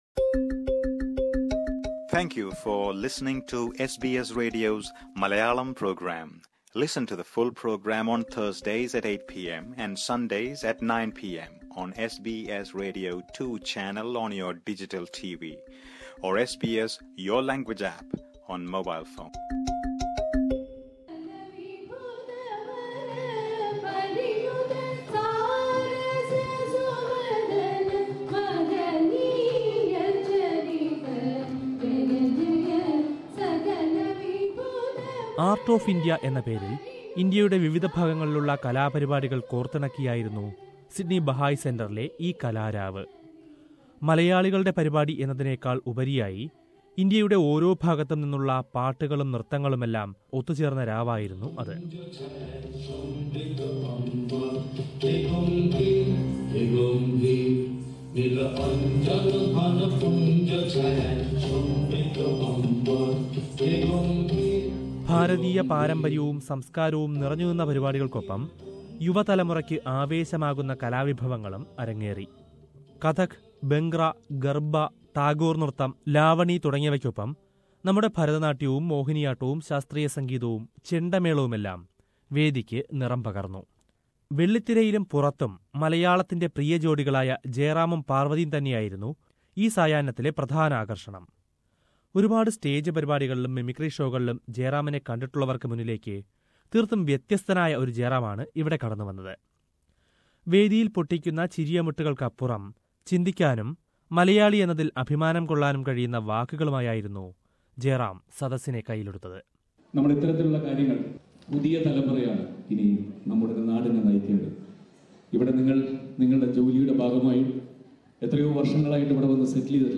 Sydney based charity organisation Ozindcare celebrated their twentieth anniversary last week. Star-couple Jayaram and Parvathy were the chief guests at the funcion. Let's listen to a report on it.